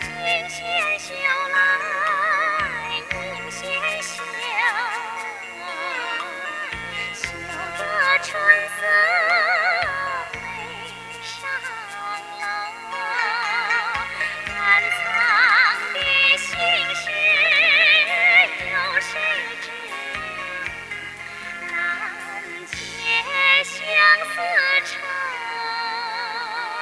od_chineseradio.wav